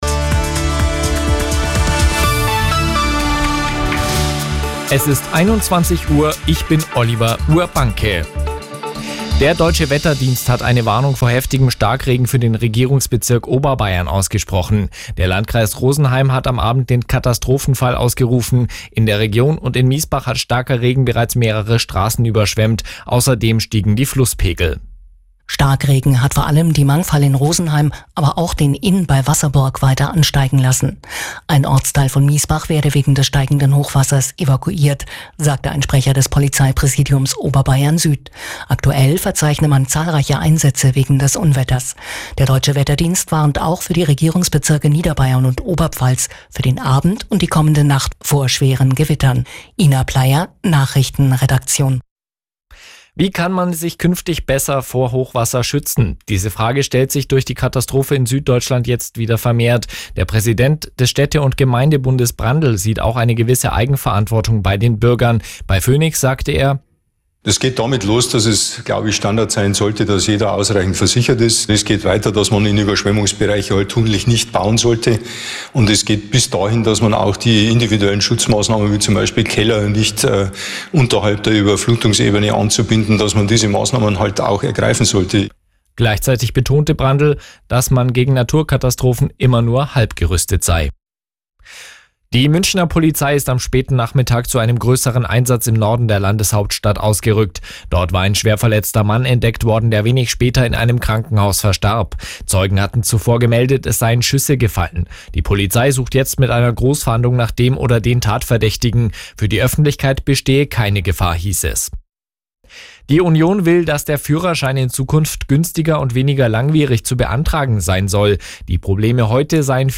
Die aktuellen Nachrichten von Radio Arabella - 04.06.2024